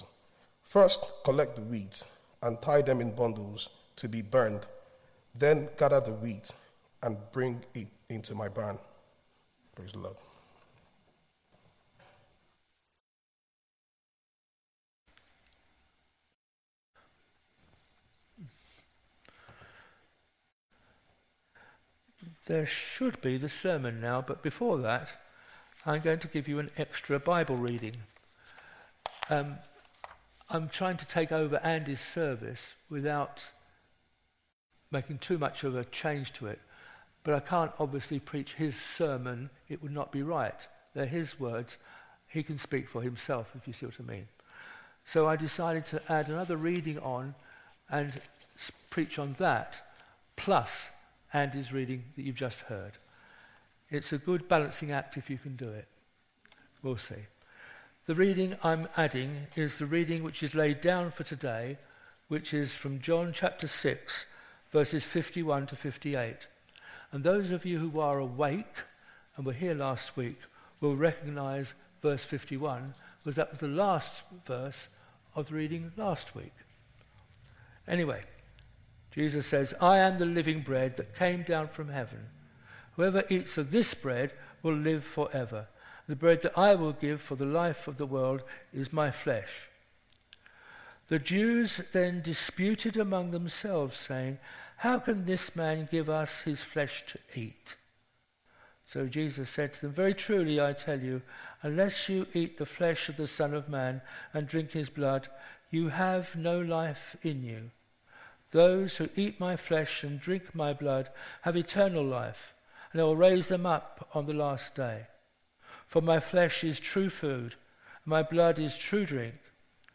Service Type: Sunday Morning
08-18-sermon.mp3